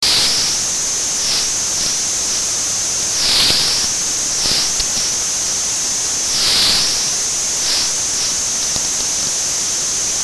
Ultrasound recording of “speak thinking” modulated breathing via nostrils using ultrasound microphones.
ultrasound-of-breathing.mp3